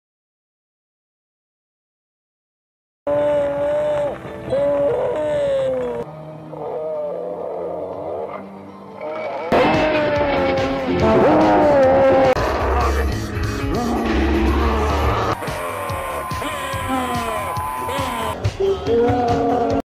zozobra voices sound effects free download